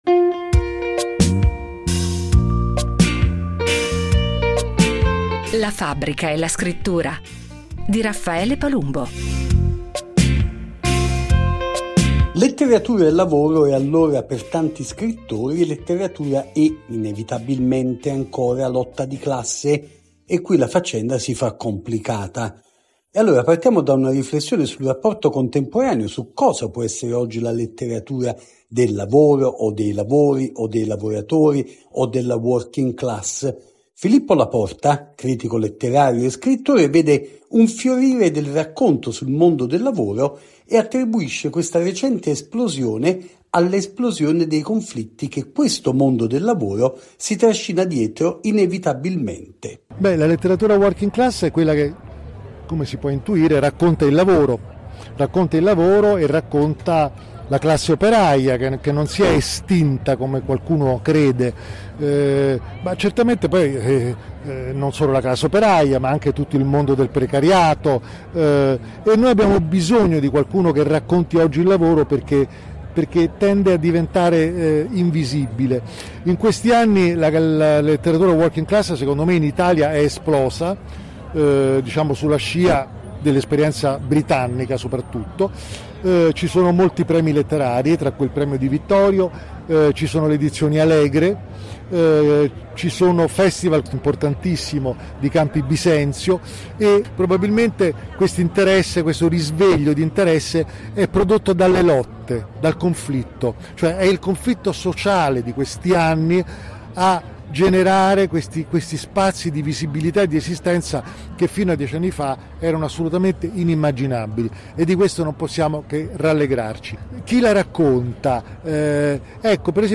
ha raccolto le voci e le testimonianze di scrittori e attivisti che si sono dati appuntamento alla terza edizione del Festival di letteratura working class di Campi Bisenzio, in Toscana.